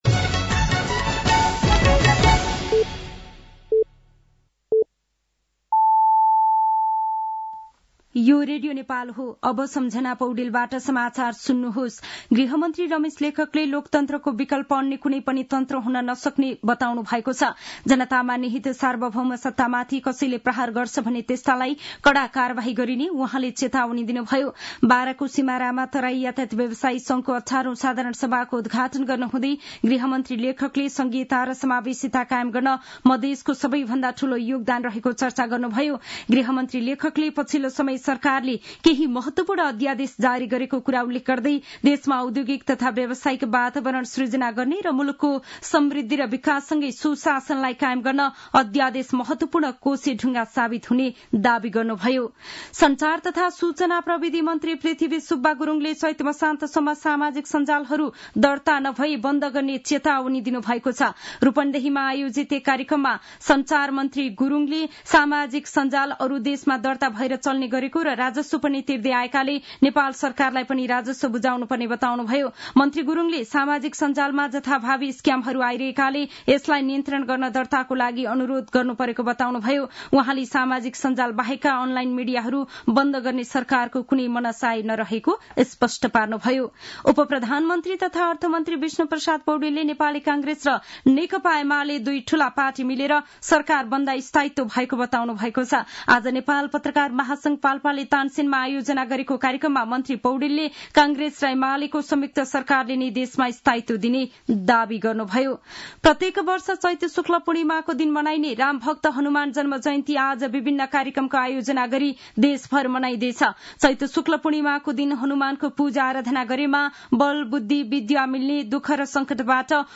साँझ ५ बजेको नेपाली समाचार : ३० चैत , २०८१
5pm-Nepali-News.mp3